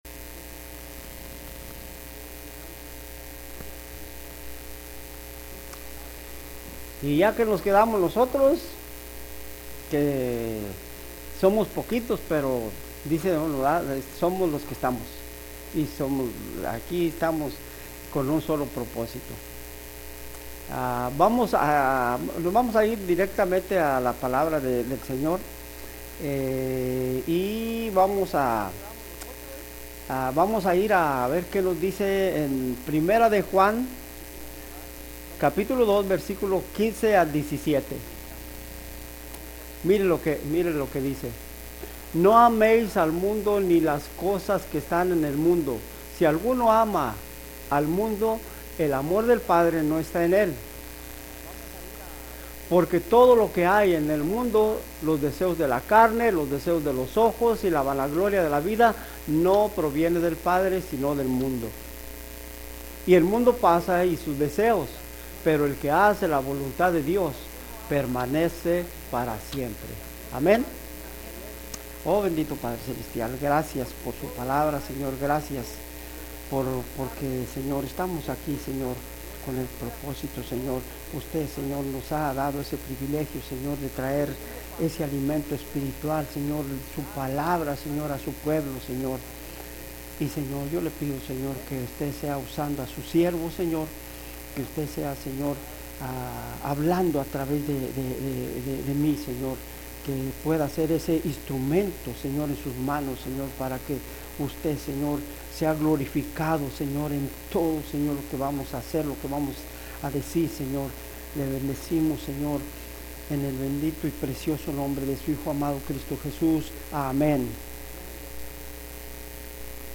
Sermons | Mercy Springs Church of the Nazarene